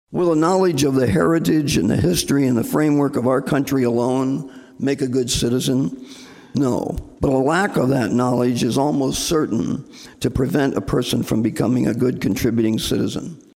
REPRESENTATIVE BOB HENDERSON OF SIOUX CITY LED HOUSE DEBATE BACK IN APRIL:.